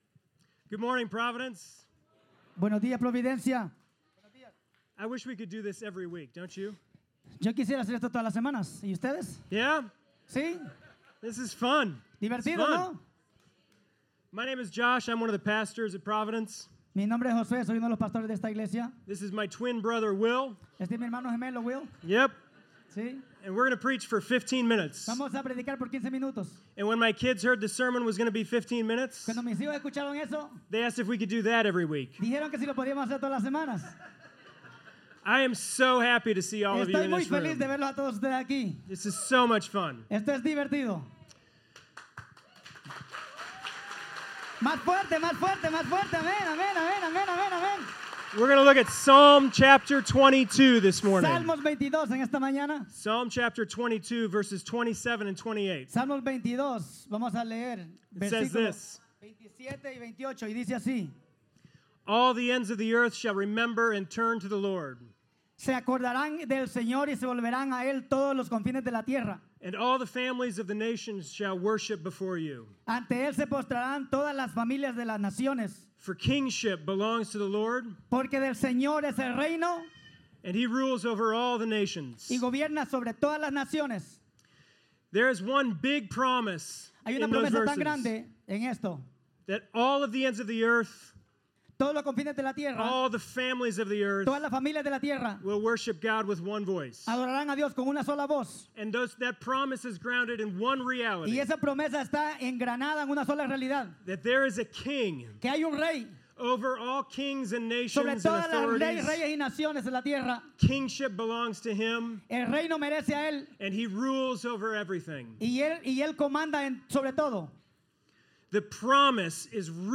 Sermons – Providence Bible Church